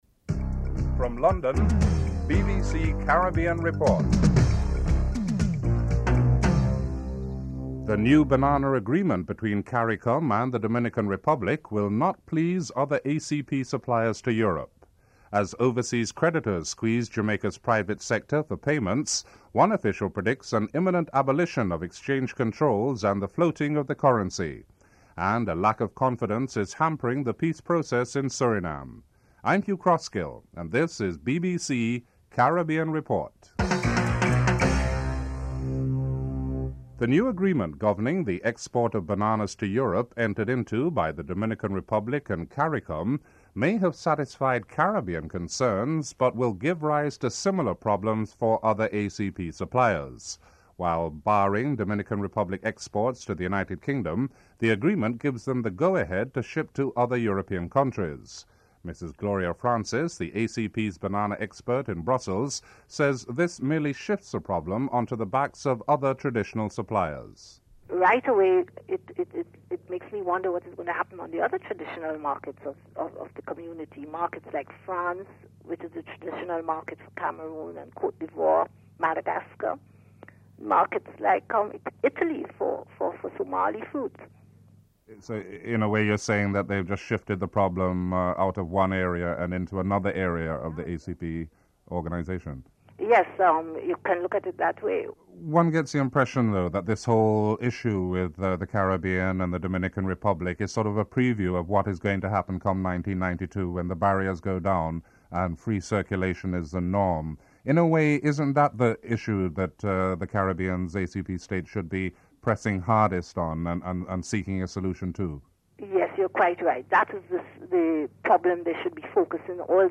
Includes a musical interlude at the beginning of the report.